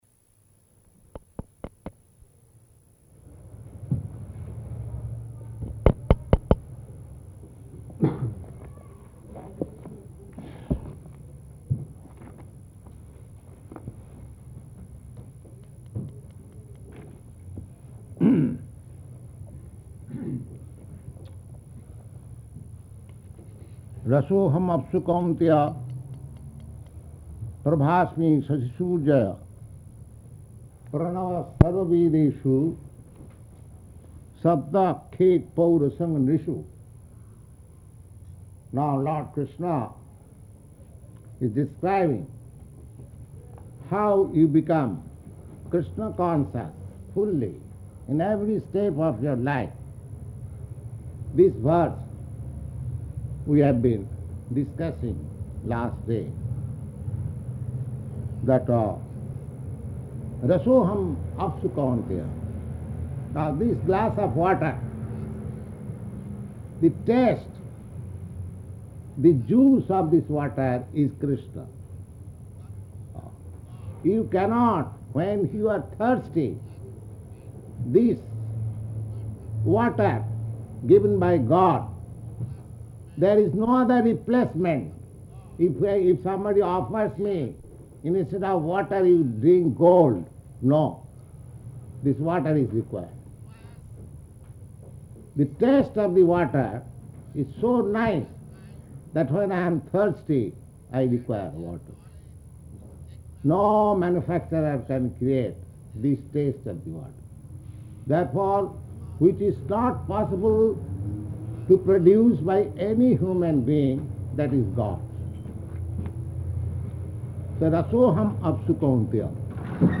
Type: Bhagavad-gita
Location: New York
661003BG-NEW_YORK.mp3